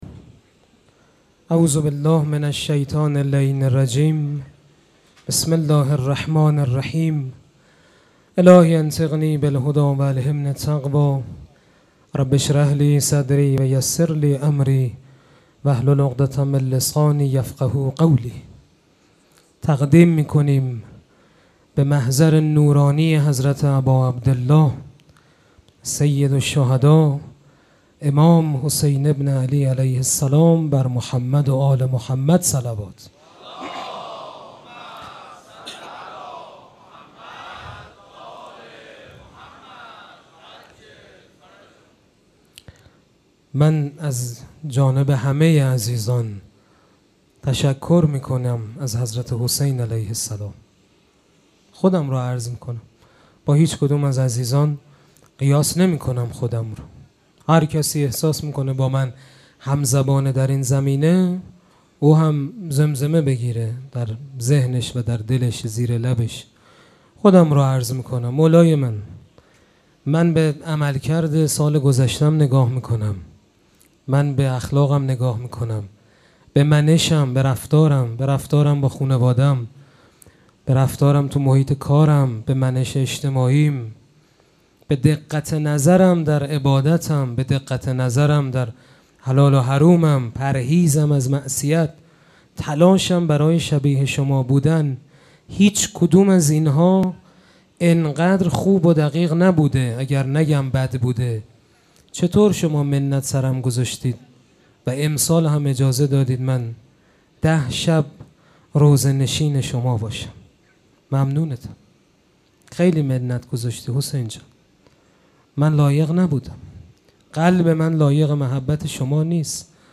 سخنرانی
مراسم عزاداری شب دهم محرم الحرام ۱۴۴۷ شنبه ۱۴ تیر۱۴۰۴ | ۹ محرم‌الحرام ۱۴۴۷ هیئت ریحانه الحسین سلام الله علیها